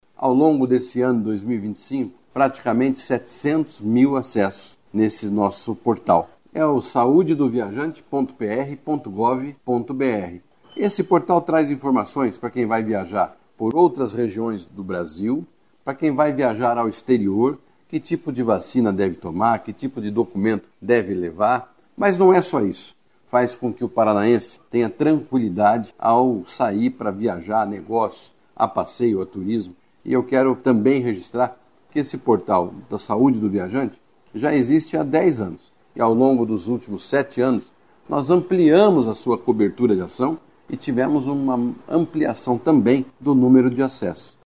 Sonora do secretário da Saúde, Beto Preto, sobre a importância do Portal Saúde do Viajante